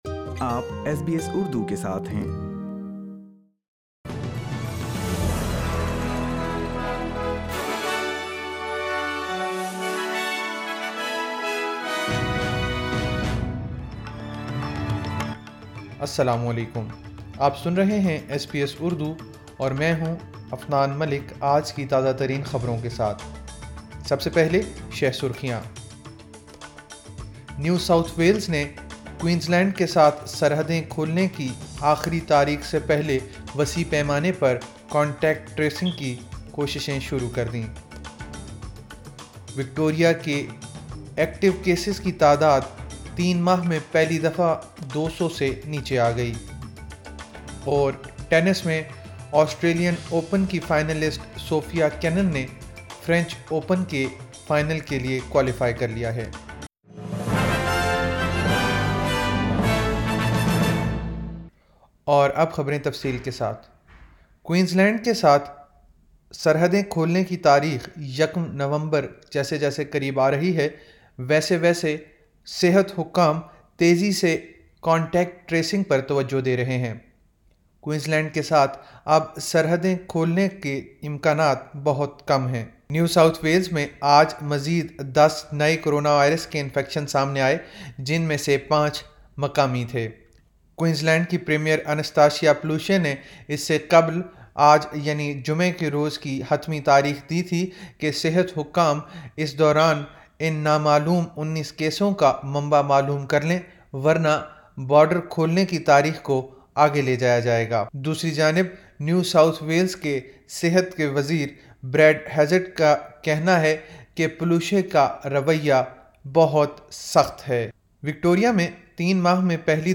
ایس بی ایس اردو خبریں 09 اکتوبر 2020